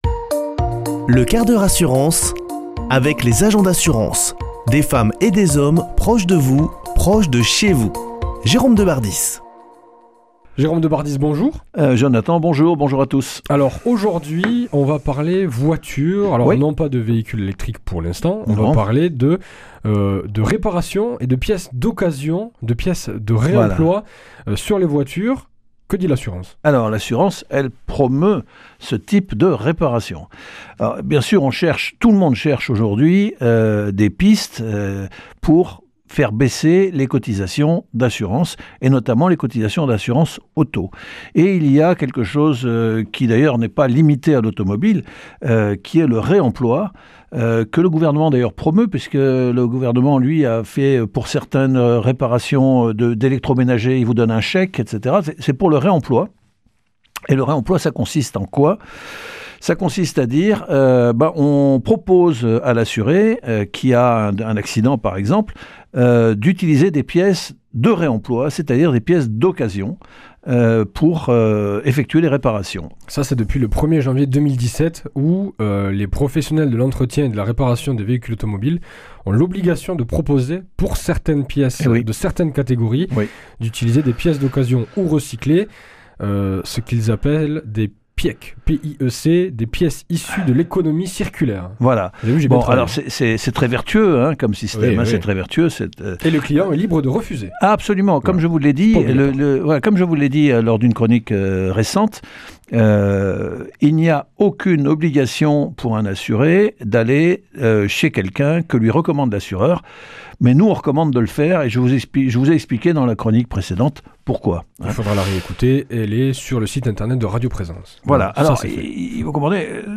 Chroniqueur